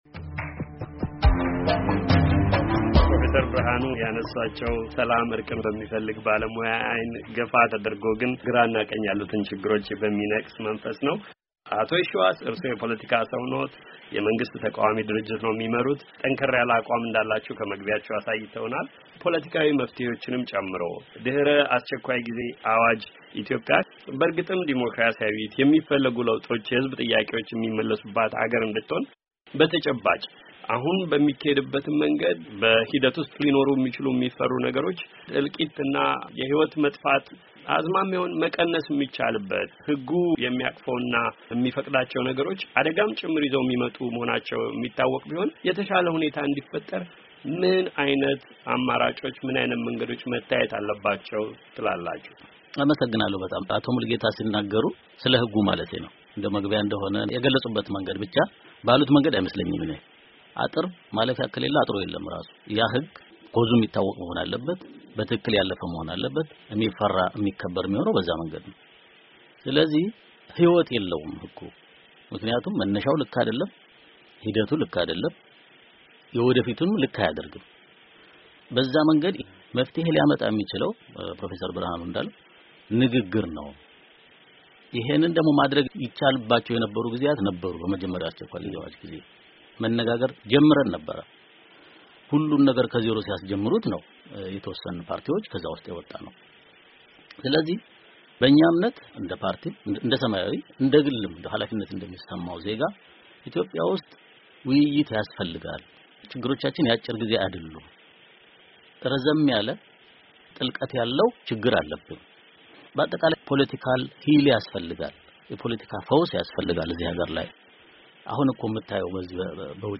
ውይይት:- ኢትዮጵያ በአስቸኳይ ጊዜ አዋጁ ማግስት